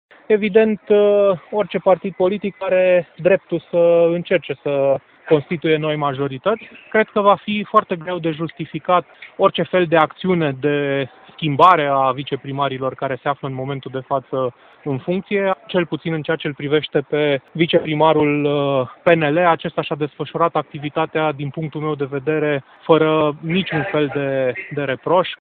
Consilierul local PNL, Ciprian Jichici, spune însă că schimbarea actualilor viceprimari ai Timişoarei este neavenită.